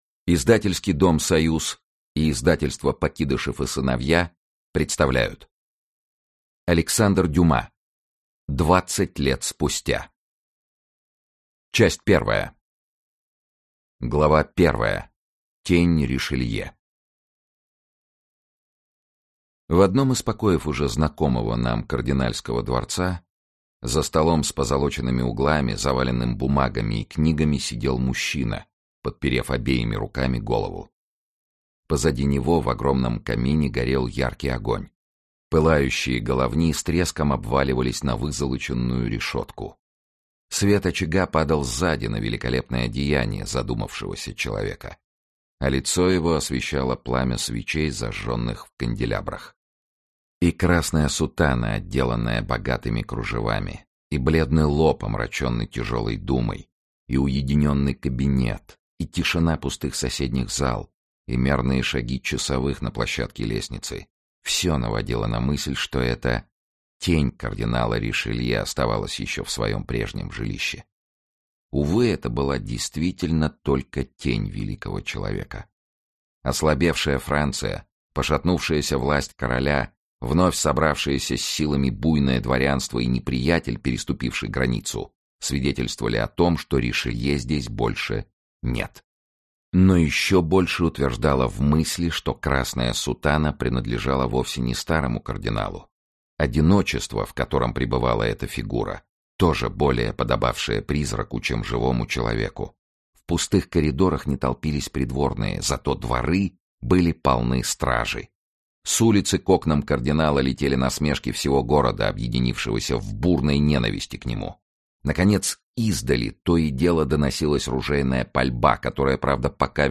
Аудиокнига Двадцать лет спустя. Часть 1 | Библиотека аудиокниг
Часть 1 Автор Александр Дюма Читает аудиокнигу Сергей Чонишвили.